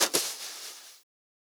56BRUSHSD3-R.wav